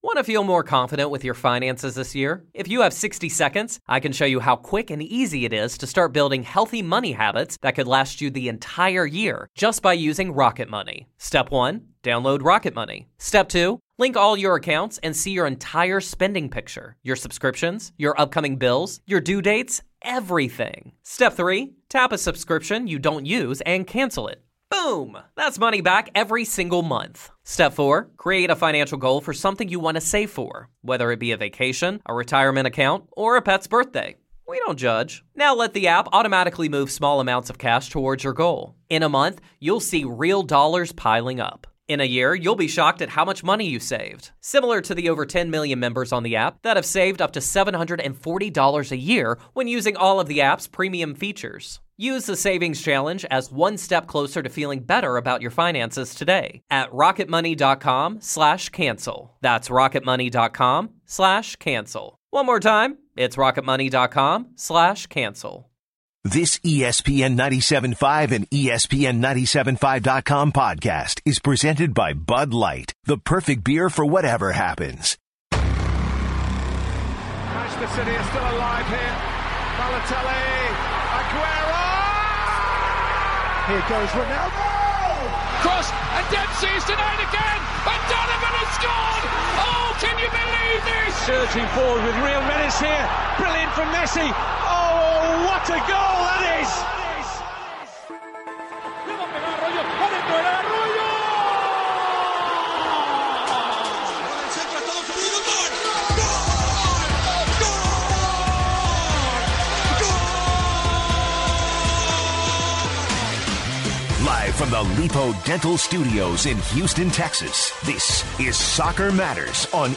takes listener's callers to talk the US Men's vs. Mexico game on tomorrow, 4/15 and also takes calls on Chelsea vs. Man United.